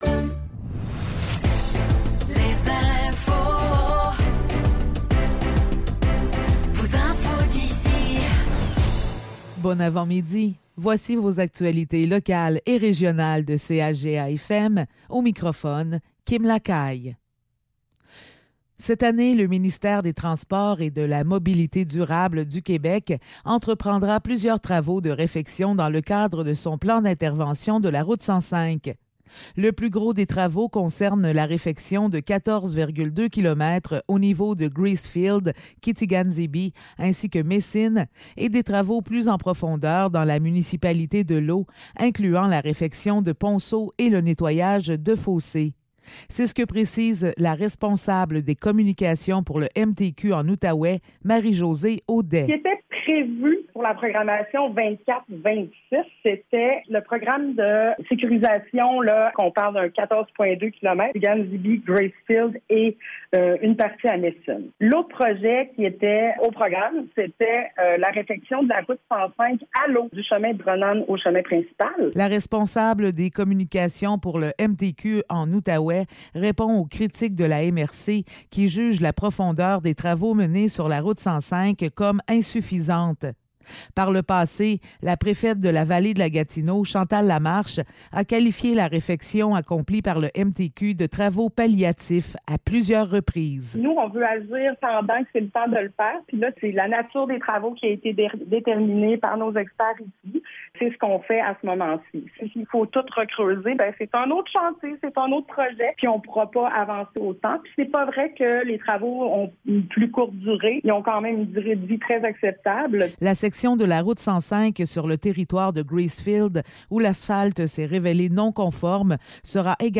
Nouvelles locales - 10 juillet 2024 - 10 h